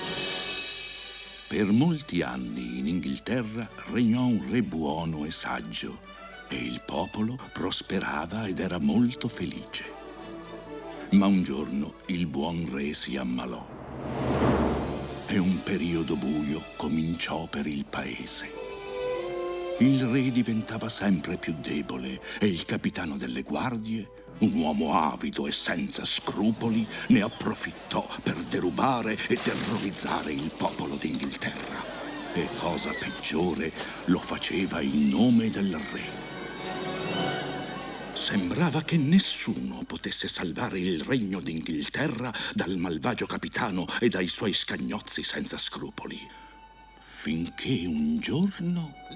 film d'animazione "Il principe e il povero", in cui doppia il Re.